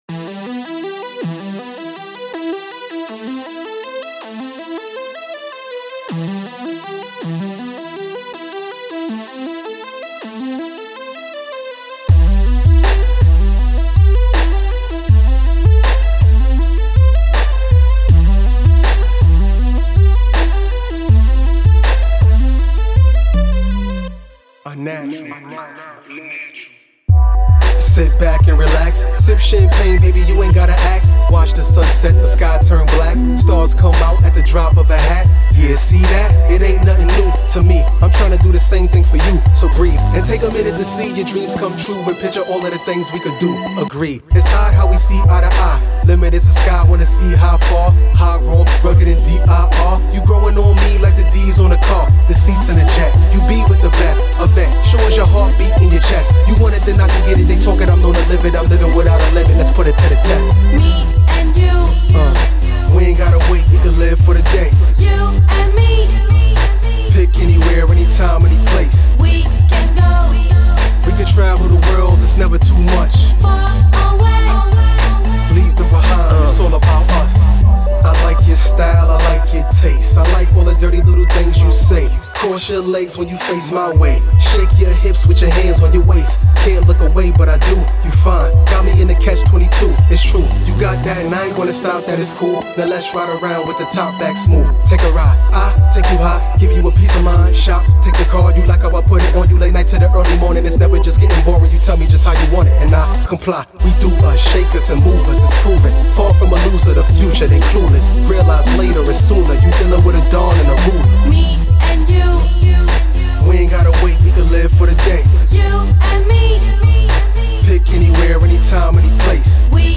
" his distinct baritone grabs you